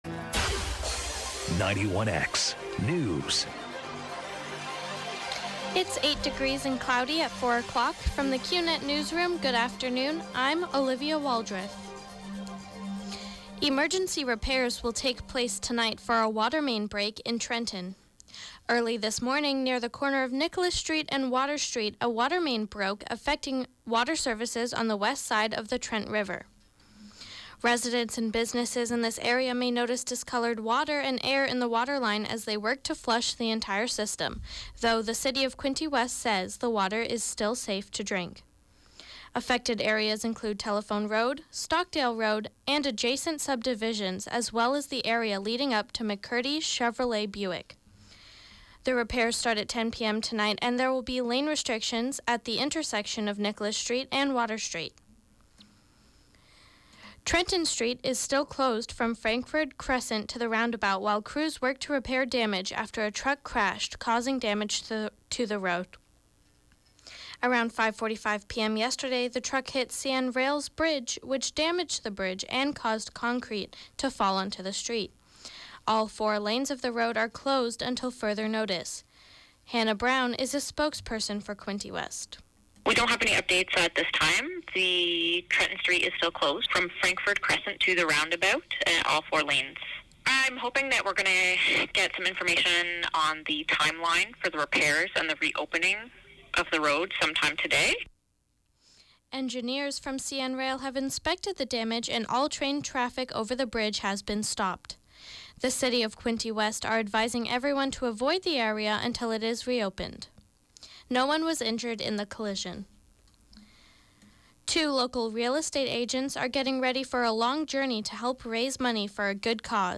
91X Newscast: Tuesday November 5 2019, 4 p.m.